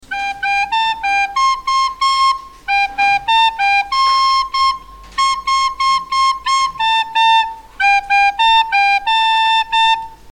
Cançons de flauta de 5è.